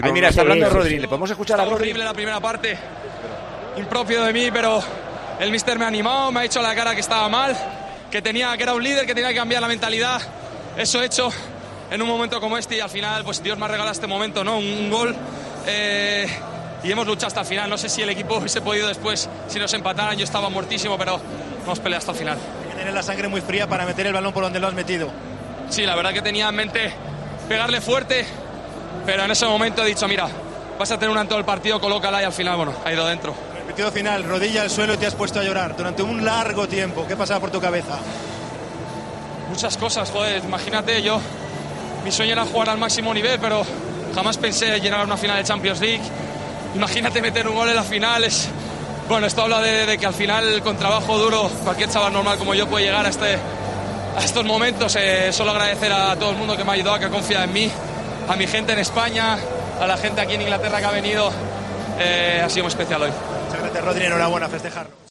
El jugador del City reconoce en declaraciones a Movistar + que Guardiola le animó en el descanso y le dijo a la cara que estaba mal.
"Esto es un sueño hecho realidad", dijo Rodri en los micrófonos de Movistar+.